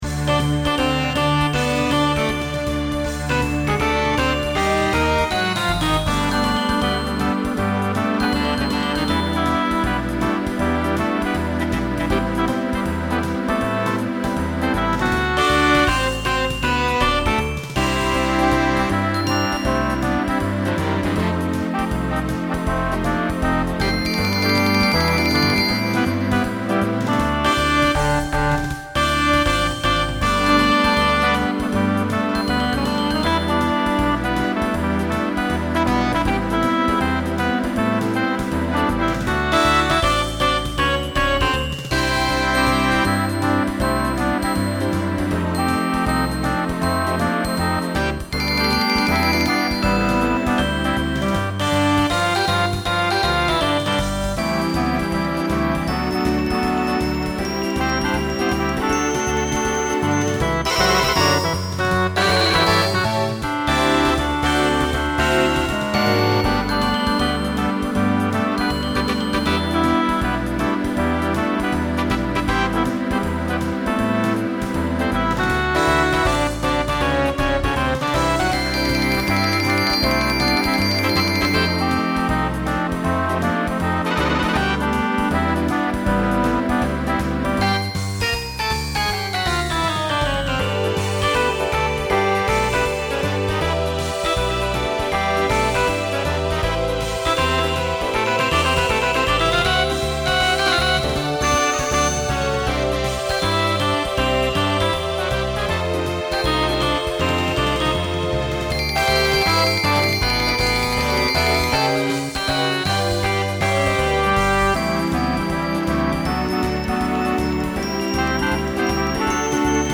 Voicing SATB Instrumental combo Genre Swing/Jazz
Mid-tempo